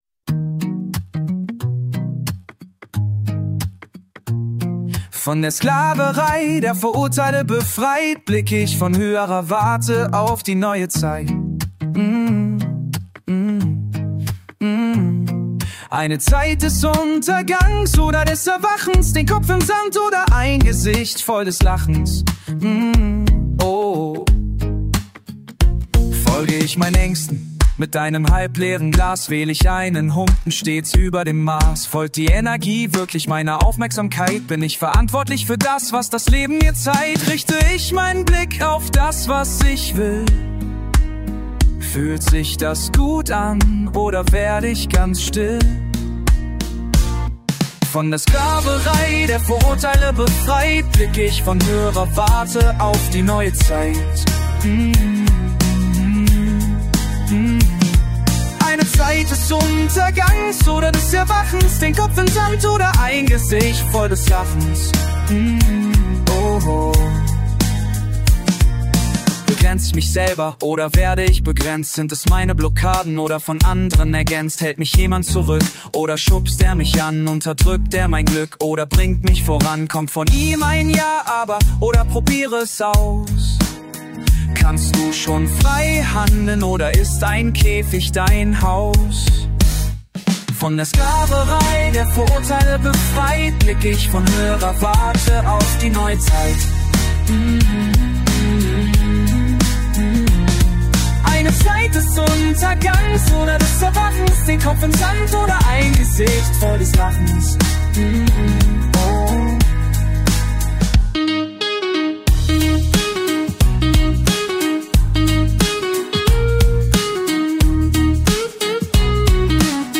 Acoustic Pop-Rock